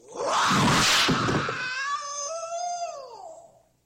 Play, download and share bruce lee scream original sound button!!!!
bruce-lee-scream.mp3